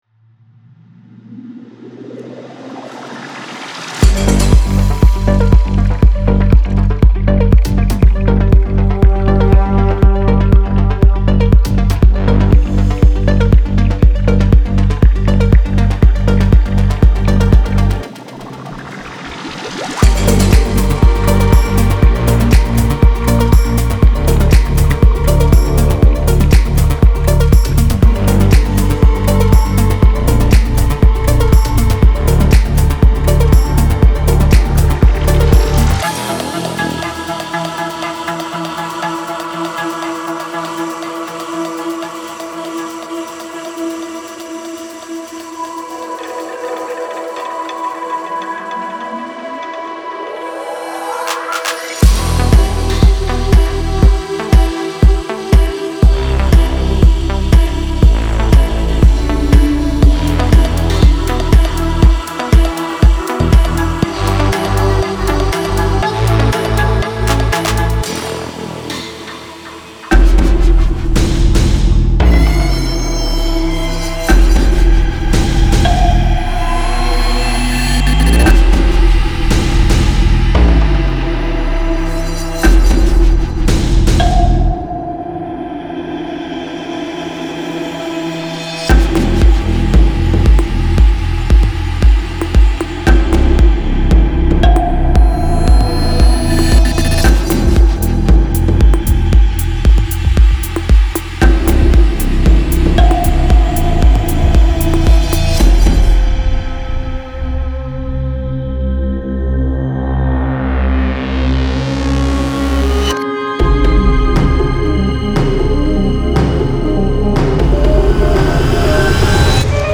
SFX for their electronic music productions
• Complete Fully Mixed And Mastered